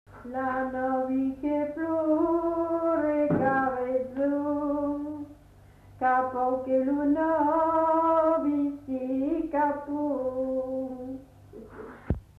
[Brocas. Groupe folklorique] (interprète)
Genre : chant
Effectif : 1
Type de voix : voix de femme
Production du son : chanté